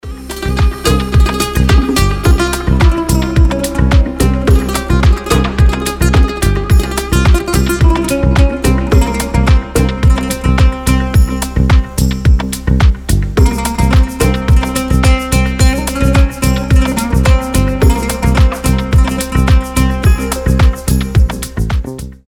• Качество: 320, Stereo
deep house
без слов
восточные
oriental house
этнические
арабские
Размеренная восточная музыка